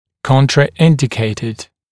[ˌkɔntrə’ɪndɪkeɪtɪd][ˌконтрэ’индикейтид]противопоказанный